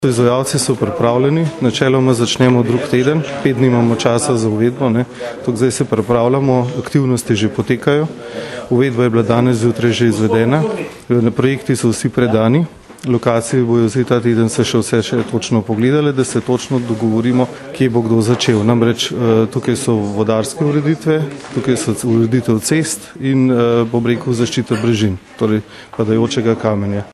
izjava_mag.borutsajoviczupanobcinetrzic.mp3 (1,6MB)